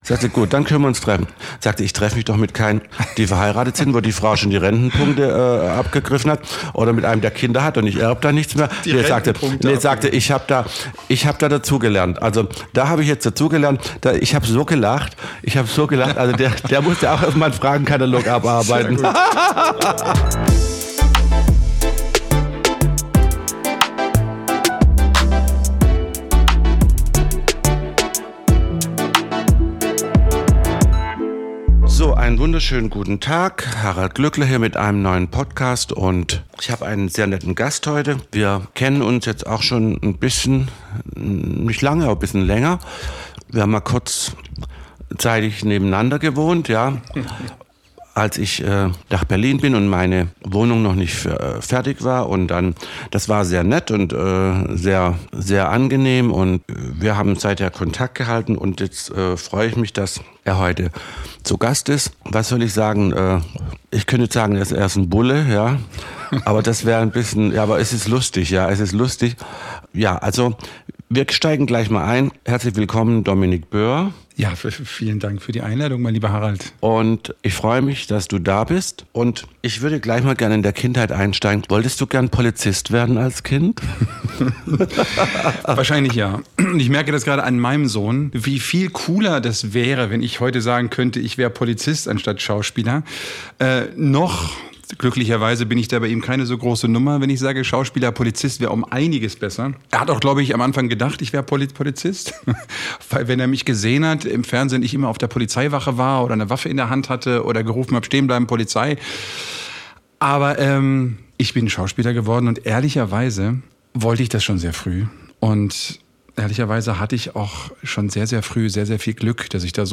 In dieser Folge haben wir einen ganz besonderen Gast: Dominic Boeer, international erfolgreicher Schauspieler und langjähriger Freund von Harald.